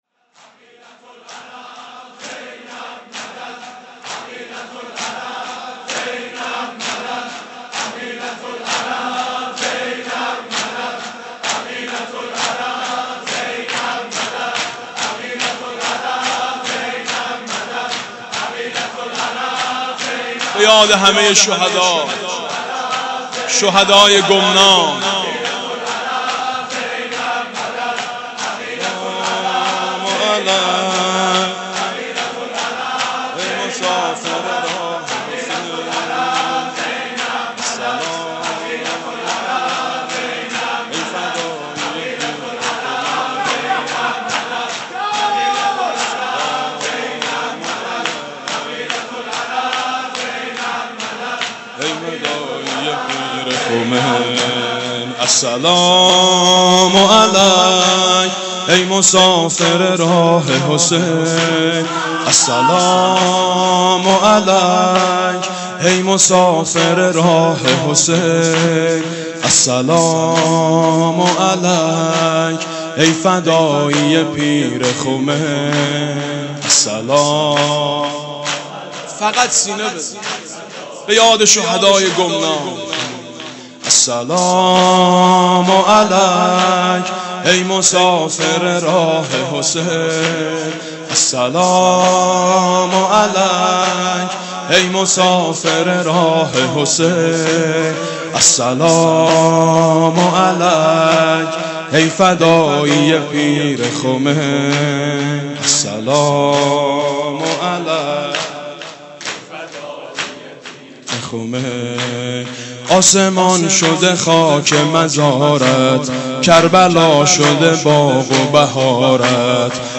«شهادت امام صادق 1390» شور: السلام علیک ای مسافر راه حسین